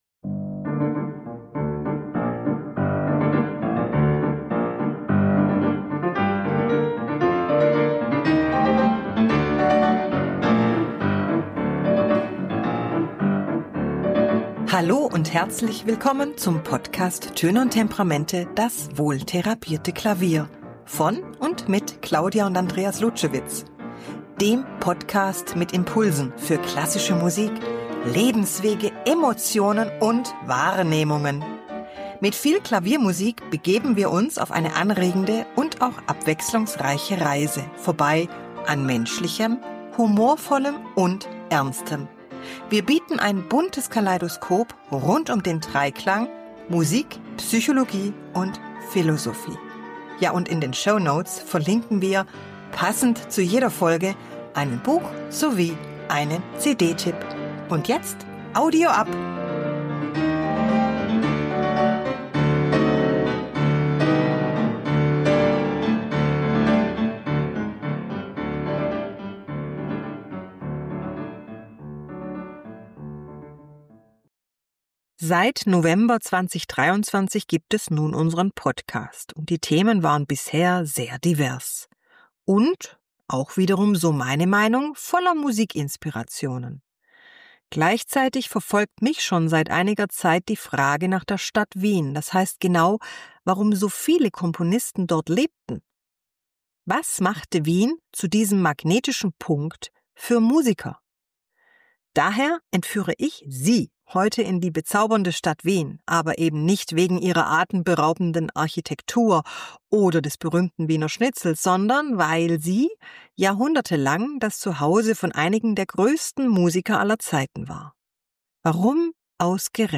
Und ach ja, als Musik wählte ich für diesen Podcast Beethovens Sonate Nr. 1 f-Moll op. 2 Nr. 1.